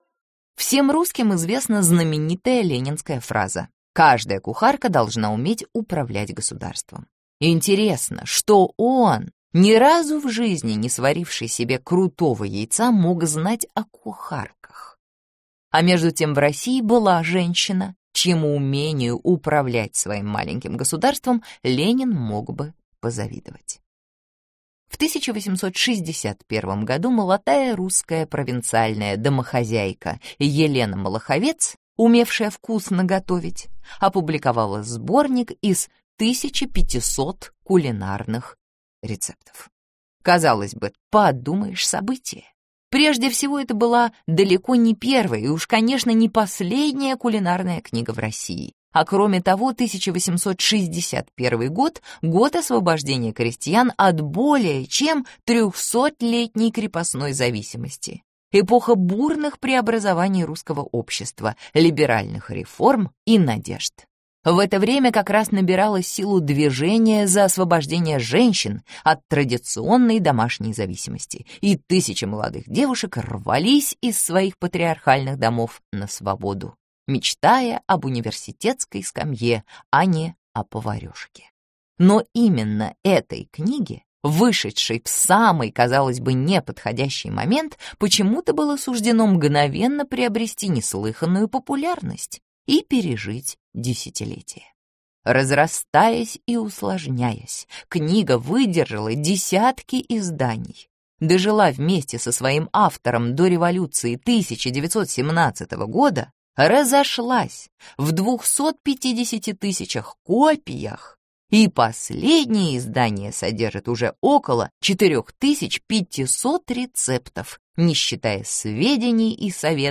Аудиокнига Войлочный век (сборник) | Библиотека аудиокниг
Прослушать и бесплатно скачать фрагмент аудиокниги